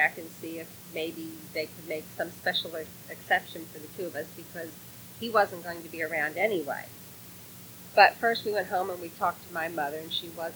You’re never going to achieve a theatrically presentable monolog out of that. The volume levels are terrible and it’s all noise.
Reduce the show to mono.